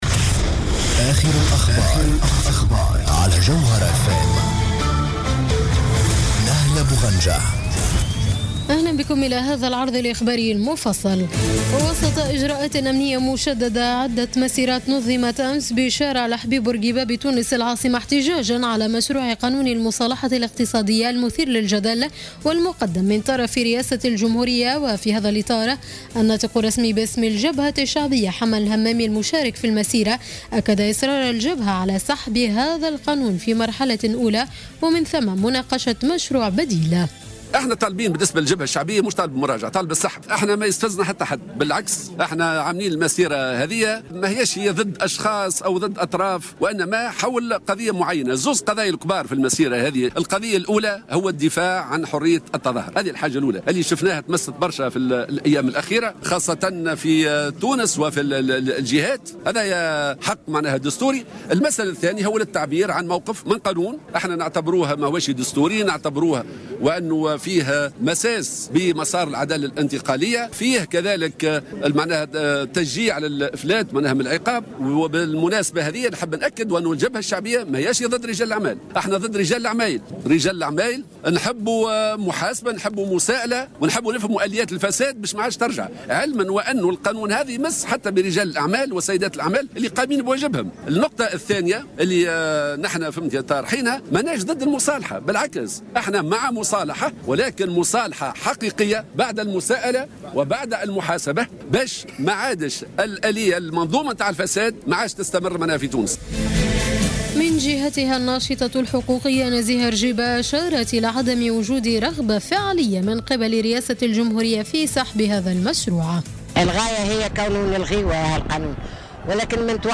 نشرة أخبار منتصف الليل ليوم الأحد 13 سبتمبر 2015